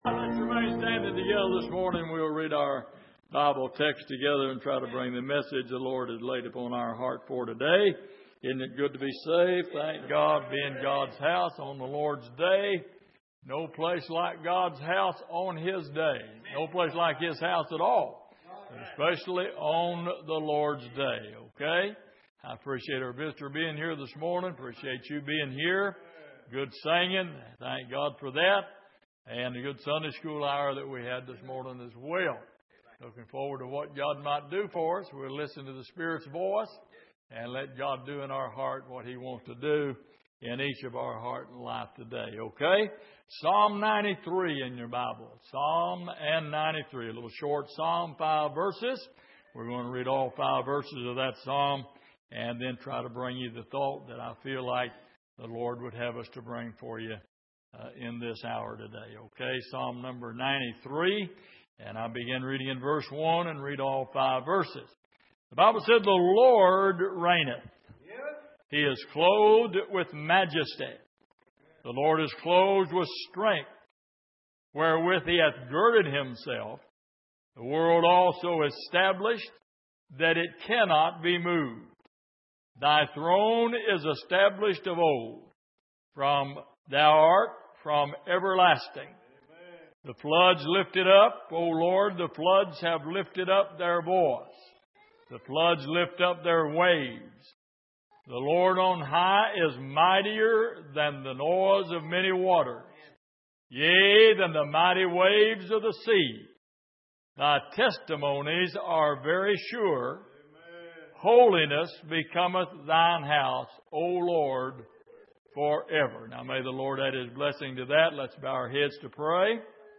Passage: Psalm 93:1-5 Service: Sunday Morning